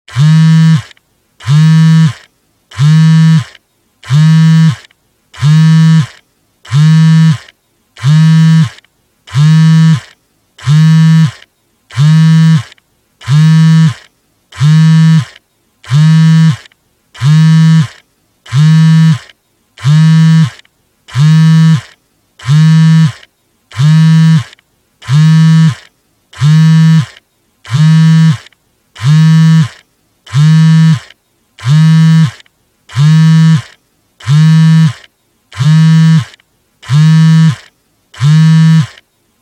携帯電話のバイブ音 着信音
ガラケーのバイブ音。ブーンブーンブーンブーンブーンブーンブーン